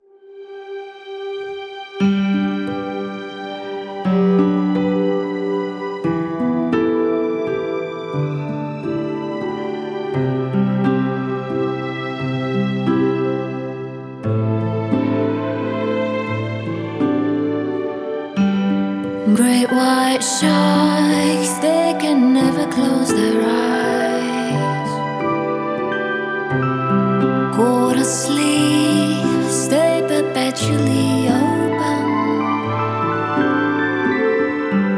Relaxation New Age